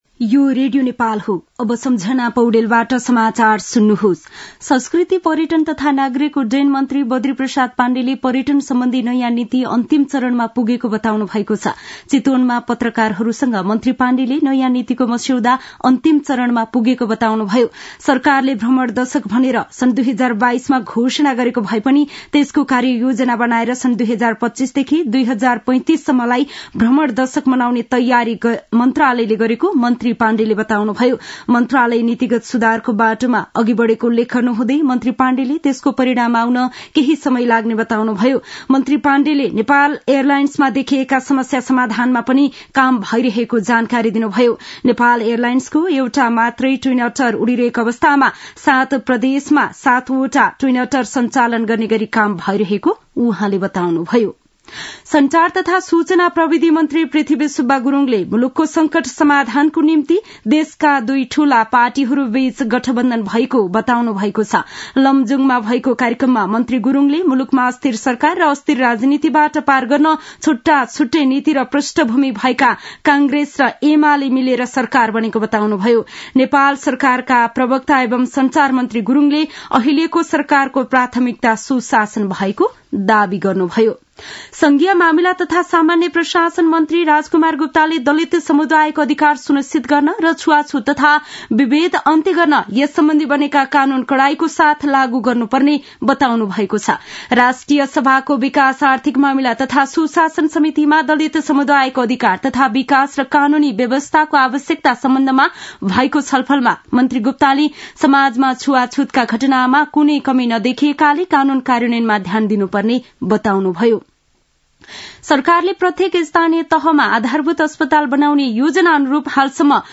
दिउँसो १ बजेको नेपाली समाचार : ६ पुष , २०८१
1-pm-nepali-news-1-15.mp3